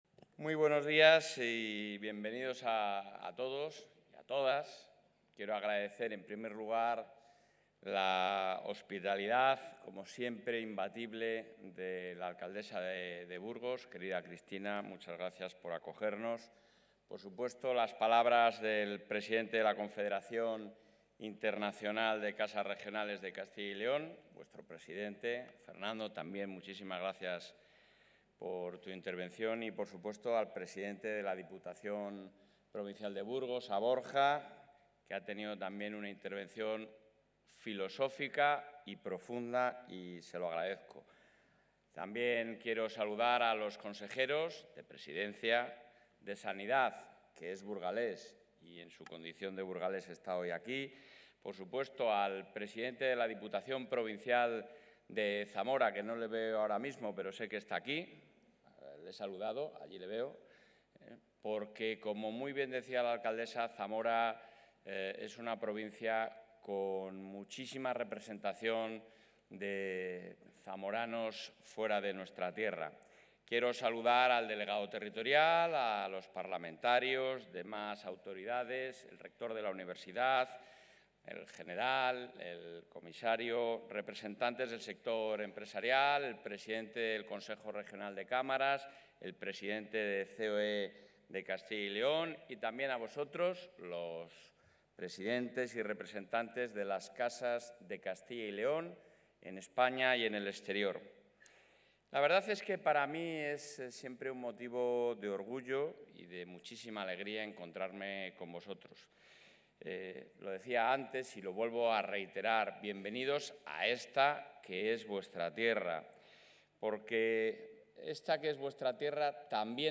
El presidente de la Junta de Castilla y León, Alfonso Fernández Mañueco, ha inaugurado hoy en Burgos el III Congreso...
Intervención del presidente de la Junta.